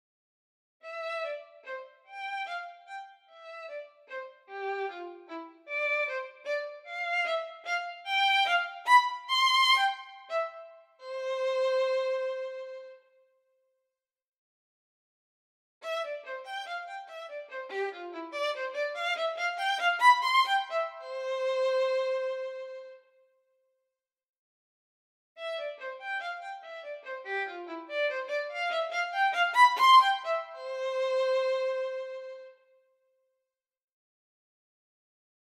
sounds soft and nice in the p-mf - range.
0,3s - sample (played in ff ).